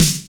kits/RZA/Snares/WTC_SNR (49).wav at 32ed3054e8f0d31248a29e788f53465e3ccbe498